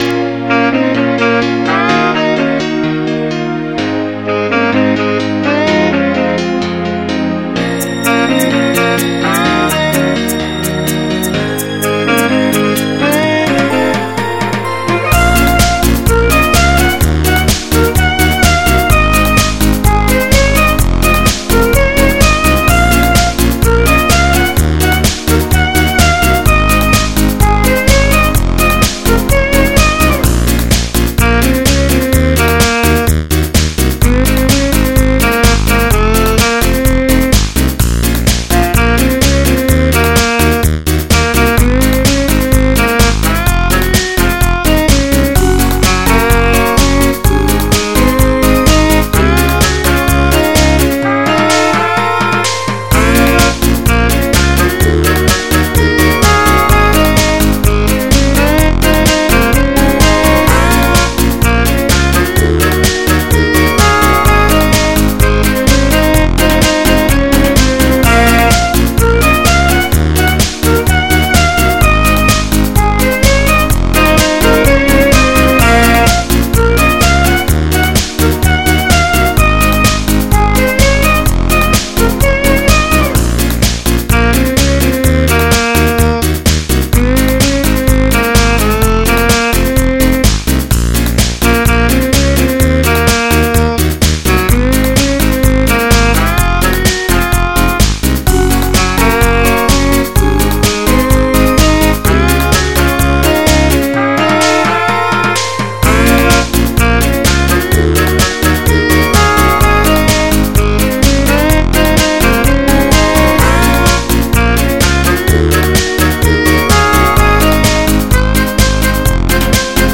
MIDI 185.38 KB MP3
Made using FL Studio